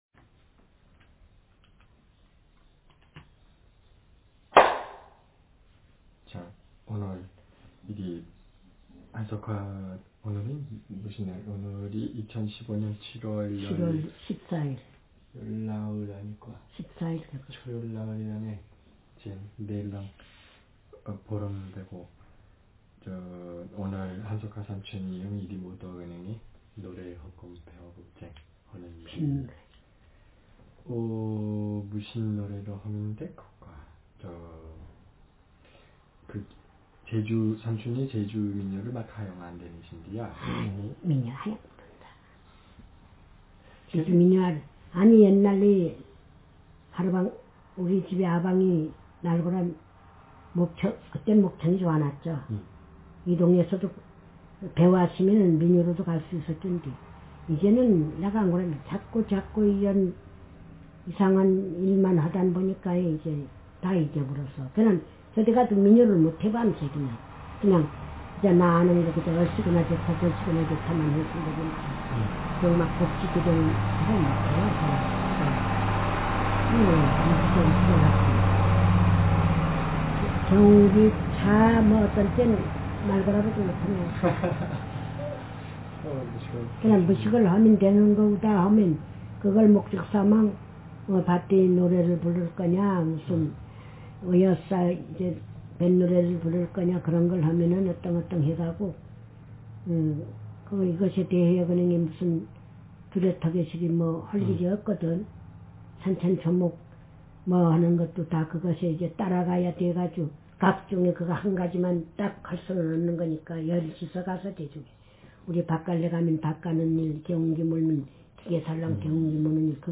Speaker sex f Text genre conversation